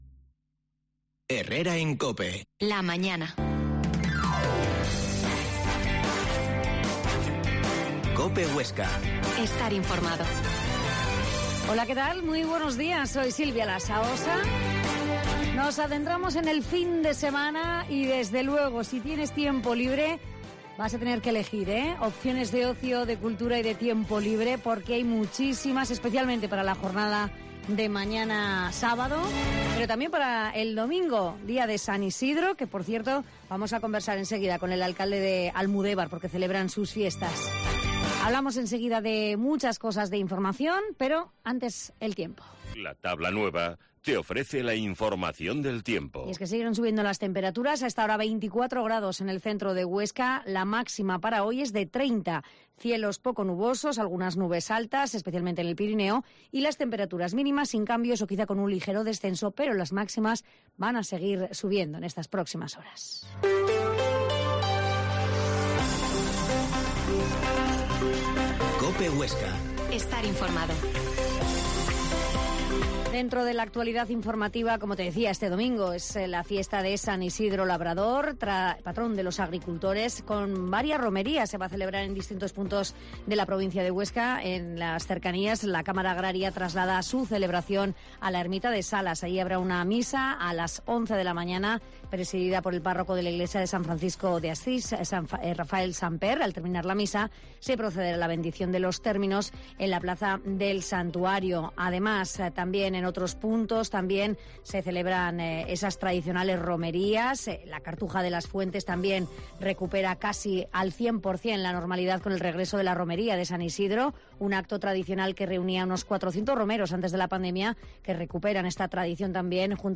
Herrera en COPE Huesca 12.50h Entrevista al alcalde de Almudevar, Antonio Labarta